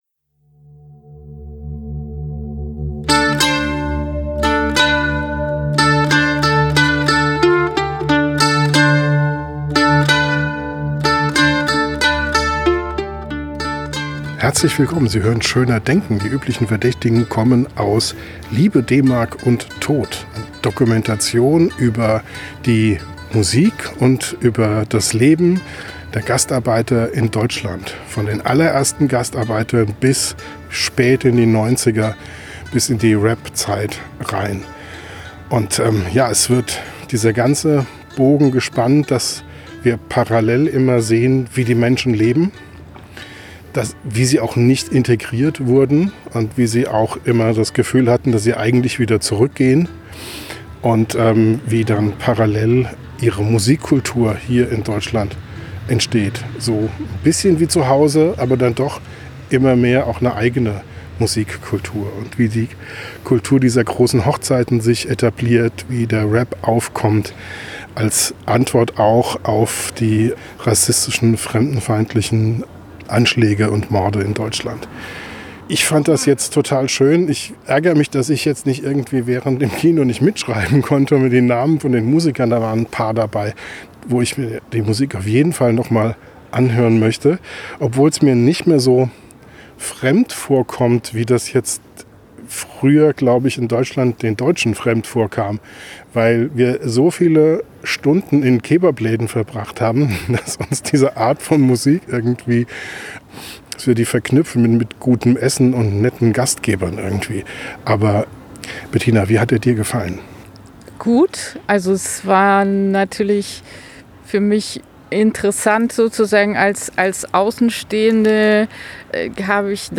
Im Podcast direkt nach dem Kino diskutieren wir über die verlorene Generation, die glaubte mit ihren Eltern in die Türkei zurückkehren zu müssen, über Fremdenhass und Anschläge auf türkische Familien und über die großartige Musik.